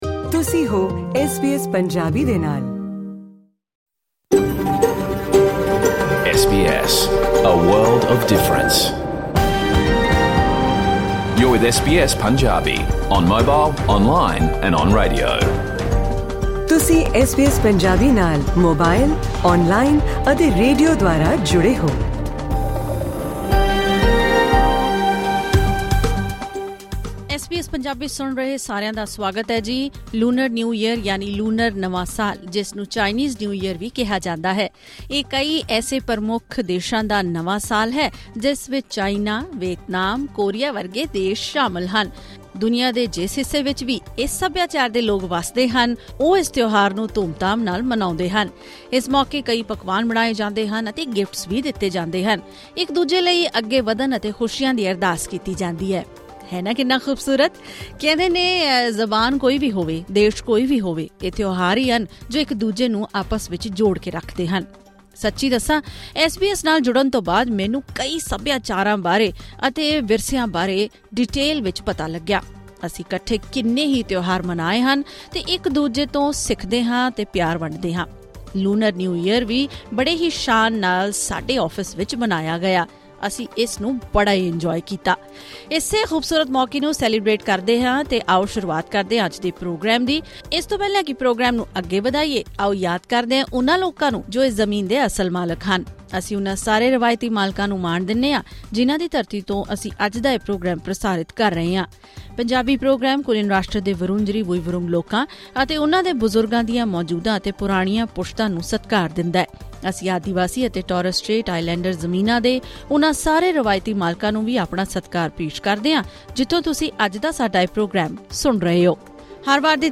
In this SBS Punjabi radio program, listen to Punjabi Chinese and Punjabi Vietnamese couples sharing their unique ways of celebrating Lunar New Year.